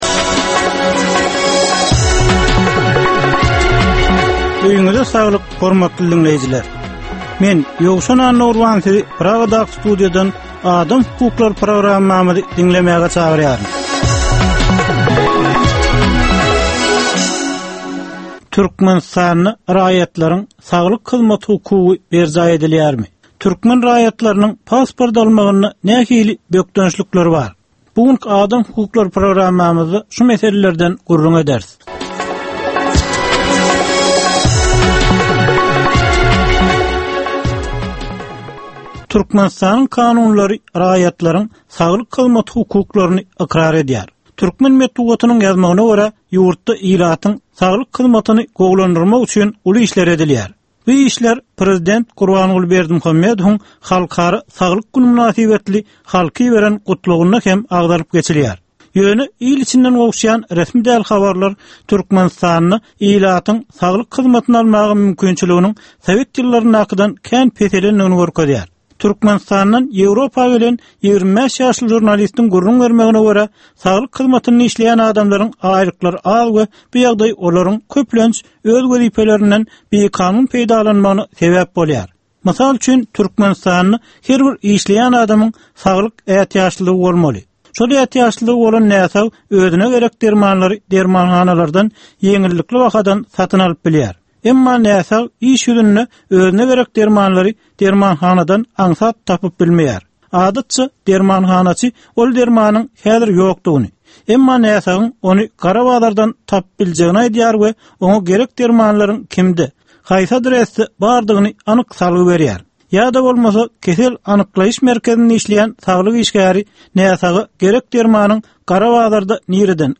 Türkmenistandaky ynsan hukuklarynyn meseleleri barada 15 minutlyk ýörite programma. Bu programmada ynsan hukuklary bilen baglanysykly anyk meselelere, problemalara, hadysalara we wakalara syn berilýar, söhbetdeslikler we diskussiýalar gurnalýar.